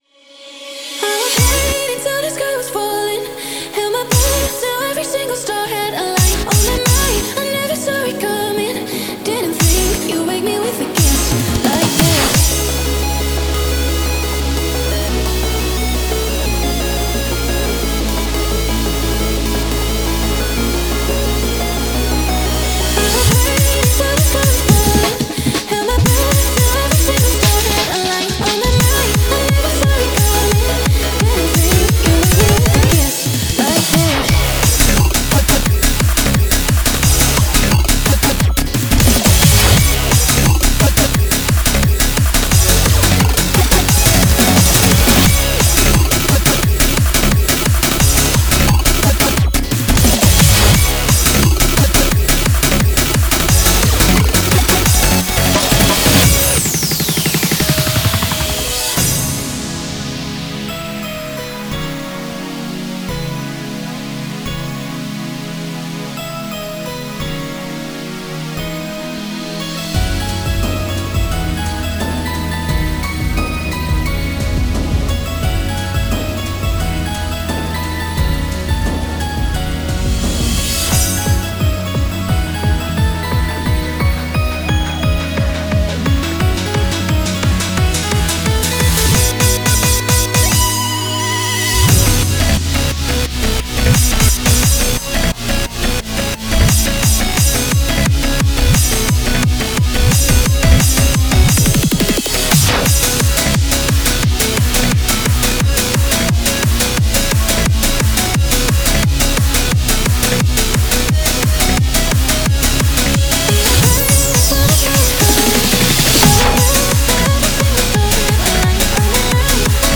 BPM175
Audio QualityPerfect (High Quality)
[Genre: TECHCORE]